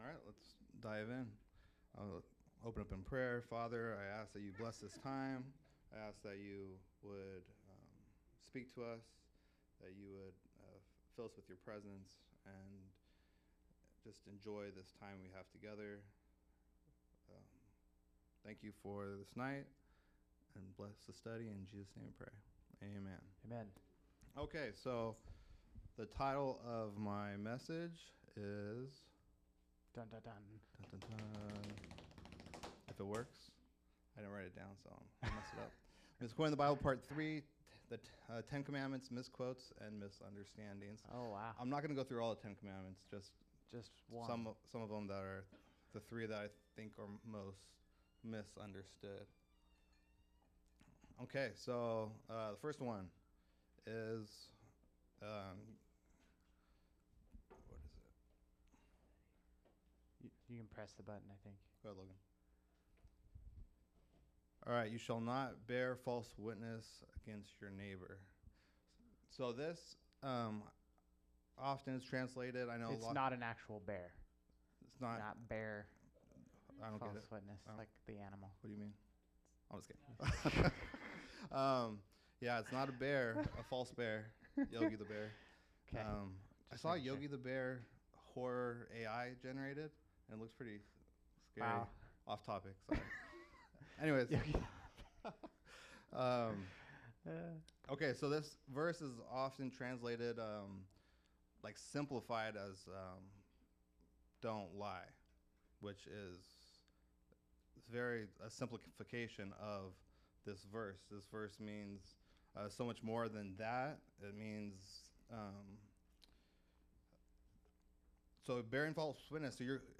Misquoting the Bible Discussion